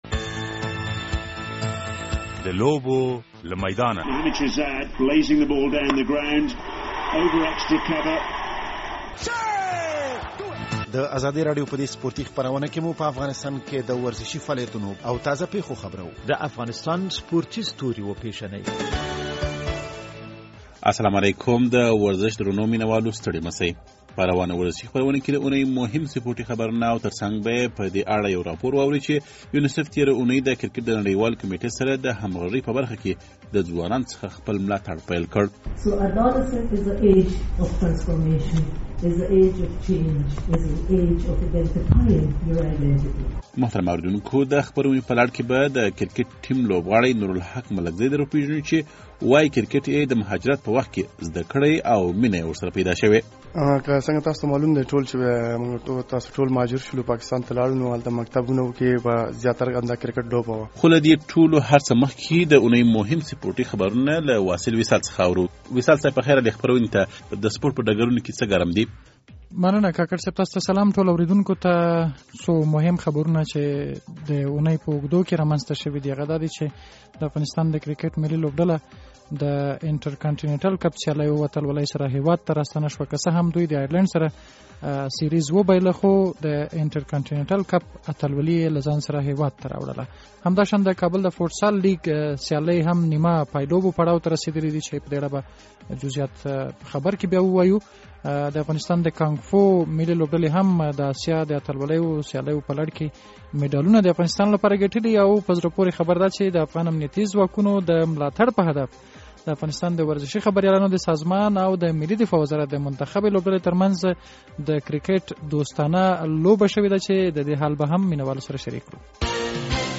د ازادي راډيو د دې اوونۍ د لوبو له میدانه په ورزشي خپرونه کې د اوونۍ مهم سپورټي خبرونه او ترڅنګ به یې په دې اړه یو راپور واورئ چې د یونسېف ادارې تېره اوونۍ د کرکټ له نړیوالې کمېټې سره د همغږۍ په برخه کې، له ځوانانو خپل ملاټر پیل کړ.